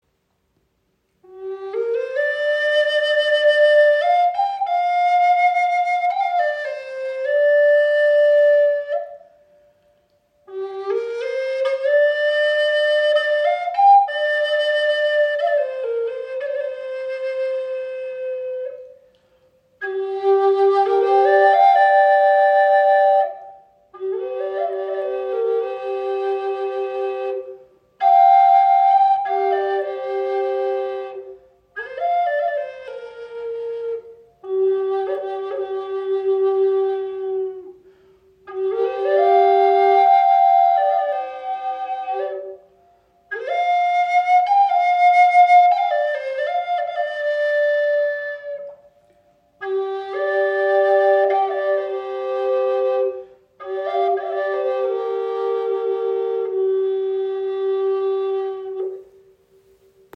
Klangbeispiel
Diese schöne Doppelflöte von High Spirit wurde aus aromatischer Zeder geschaffen. Sie schenkt Dir ein wundervolles Fibrato, kann als Soloinstrument gespielt werden oder als weiche Untermahlung Deiner Musik.